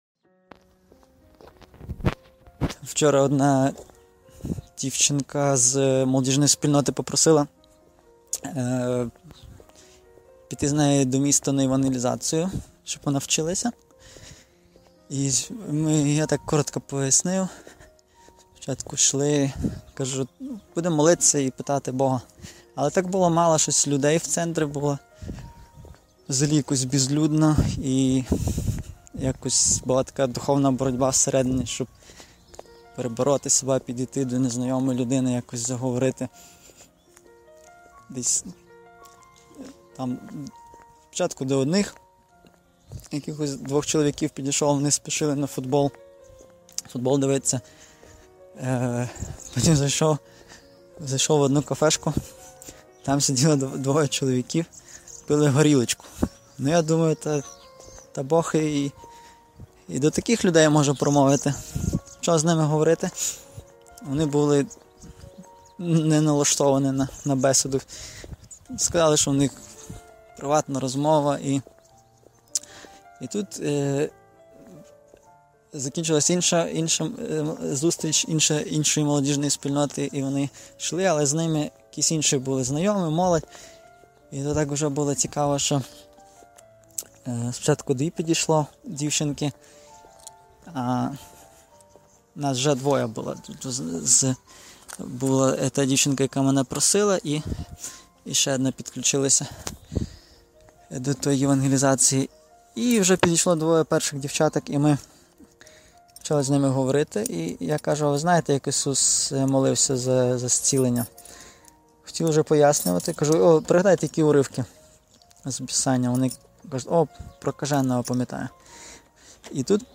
Свідчення: молитва за зцілення